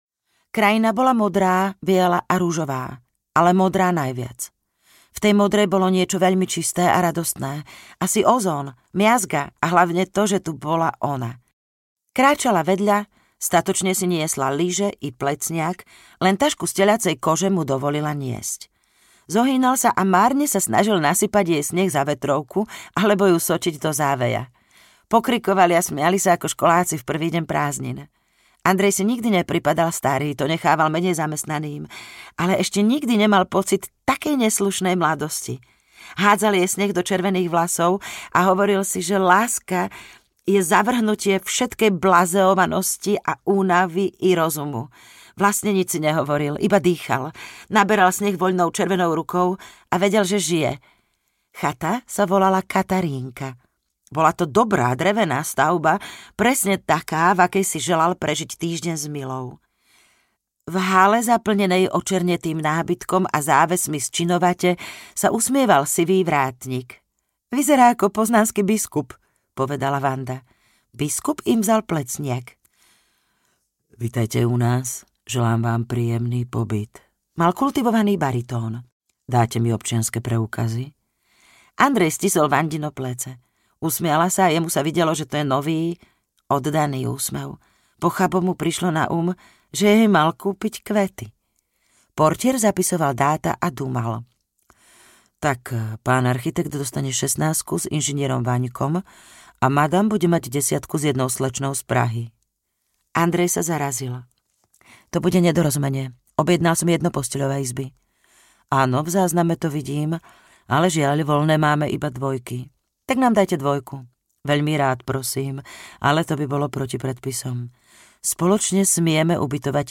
Ukázka z knihy
• InterpretZuzana Fialová